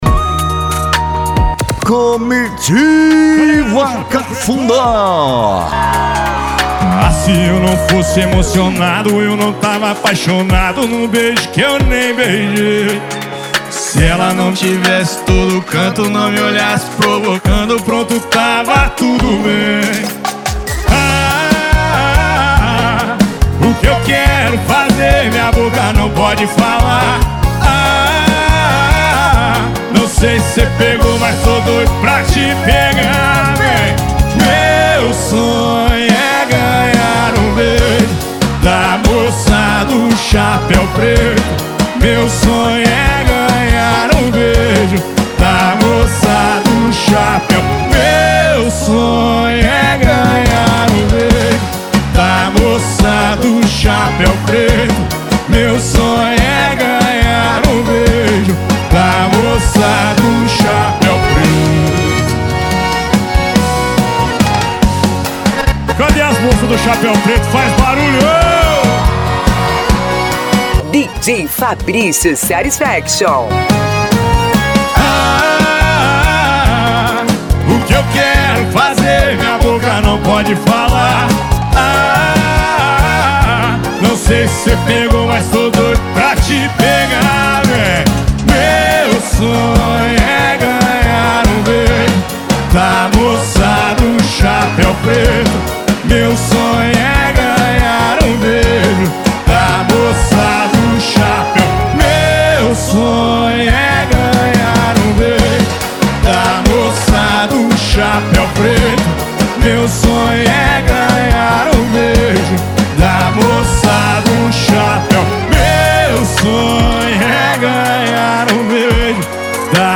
Arrocha
Funk
SERTANEJO
Sertanejo Universitario
Sets Mixados